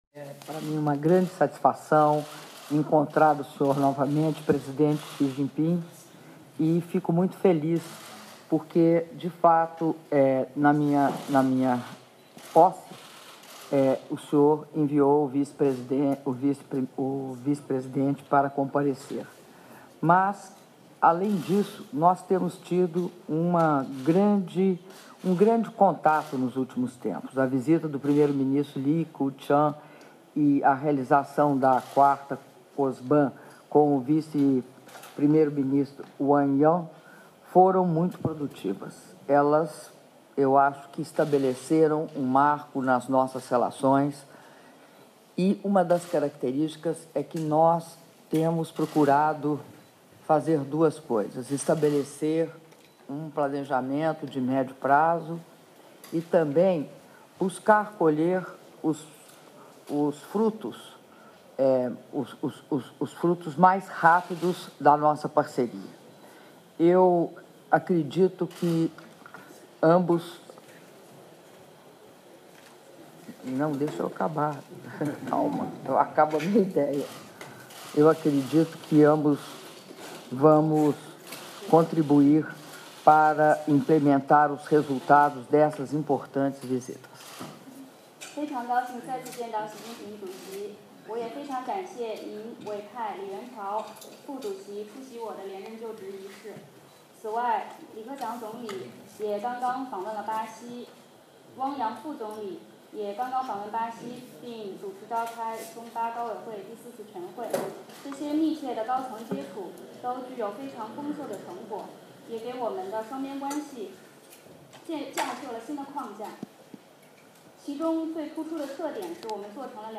Áudio da Declaração da presidenta da República, Dilma Rousseff, antes da reunião bilateral com o presidente da China, Xi-Jinping- UFA/Rússia - (02min38s) — Biblioteca